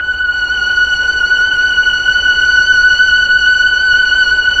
Index of /90_sSampleCDs/Keyboards of The 60's and 70's - CD1/STR_Melo.Violins/STR_Tron Violins
STR_TrnVlnF#6.wav